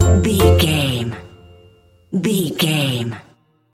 Ionian/Major
F#
Slow
orchestra
strings
flute
drums
circus
goofy
comical
cheerful
perky
Light hearted
quirky